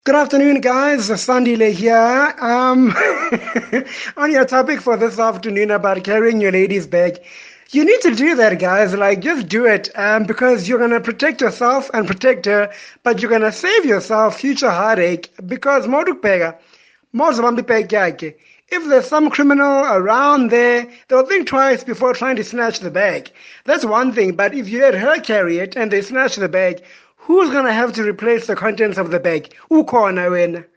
Here’s what Kaya Drive listeners had to say about men carrying their partner’s handbags: